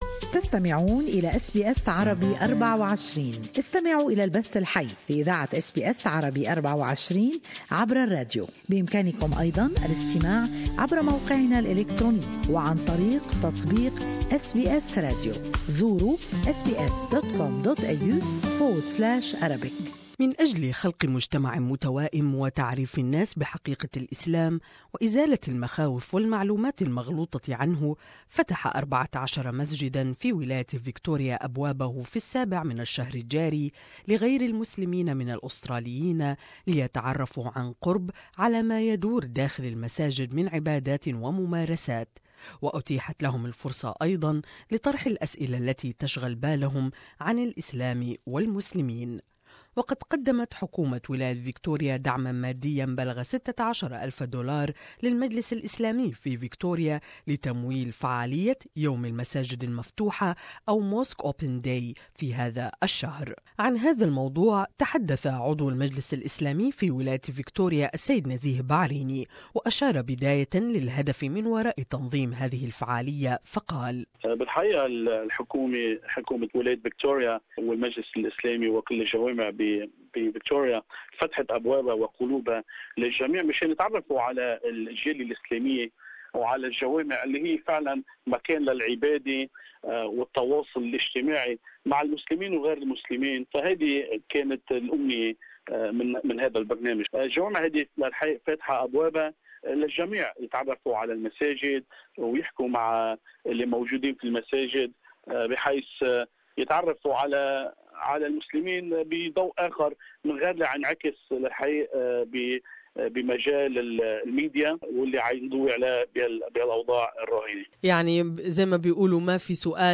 Dispelling myths about an often misunderstood Islamic religion and way-of-life has been the motivation behind the inaugural "Victorian Mosque Open Day." 13 places of worship opened their doors, providing a rare opportunity for non-Muslims to visit, participate and learn. More in this interview